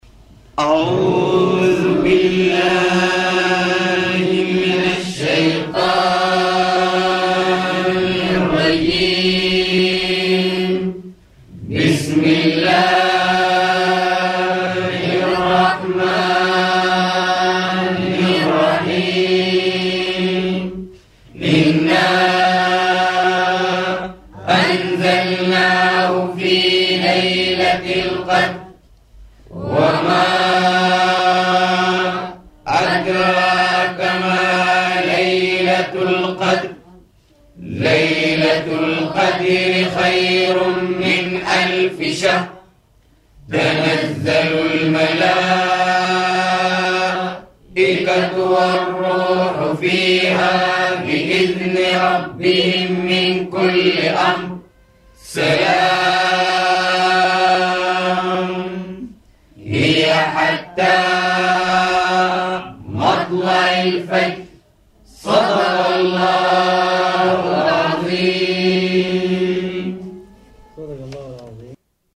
إنشاد